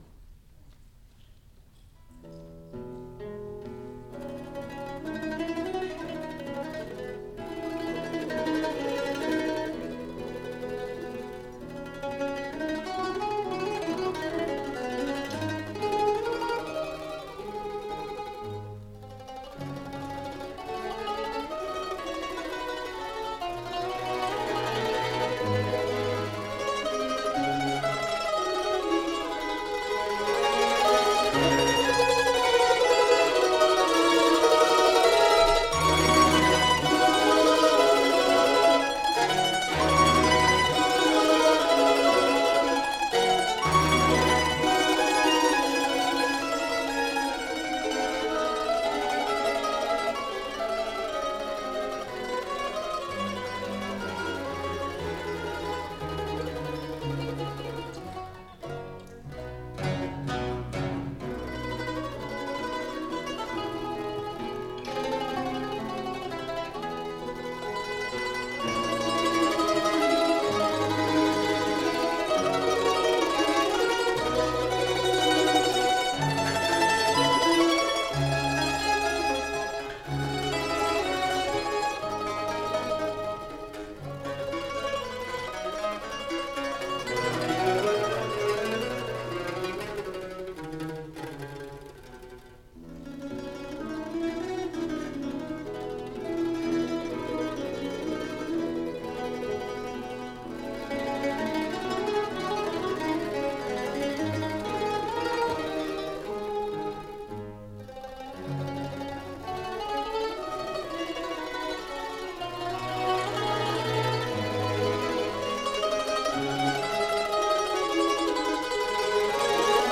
ソロとアンサンブルの夕べ(1980.7.2 府立文化芸術会館)
マンドリン アンサンブル